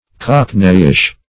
Cockneyish \Cock"ney*ish\, a. Characteristic of, or resembling, cockneys.